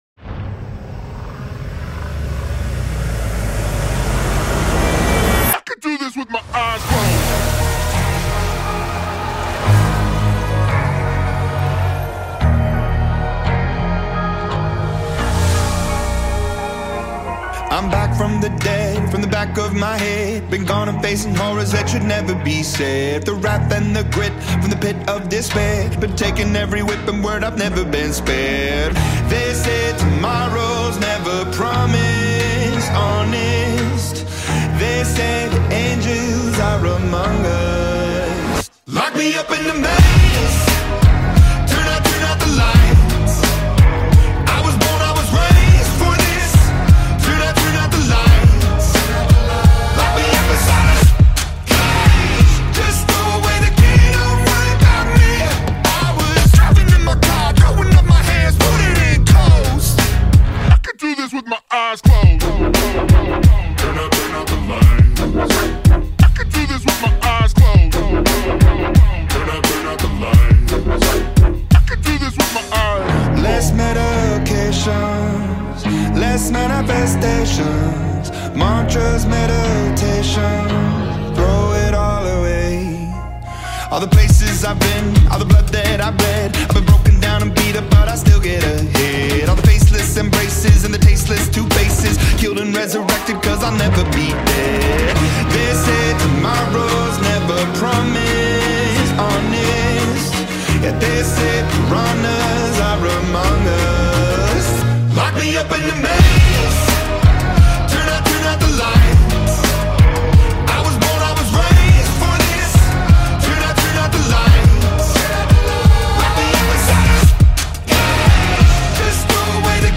Alternative song
pop rock band